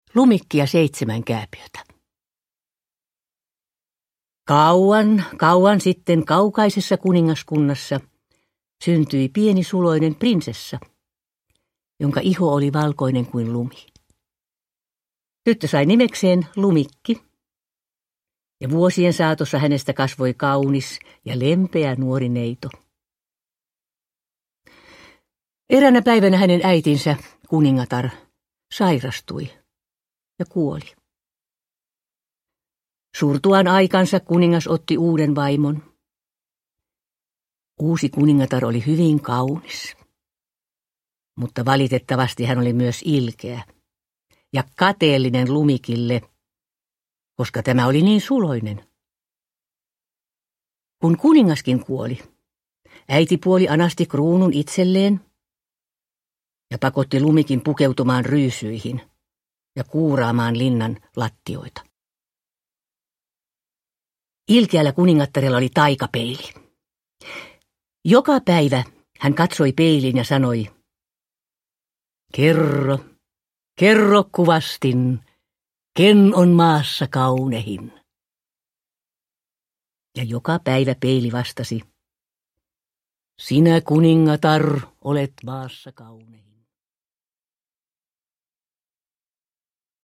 Lumikki ja seitsemän kääpiötä – Ljudbok – Laddas ner
Uppläsare: Seela Sella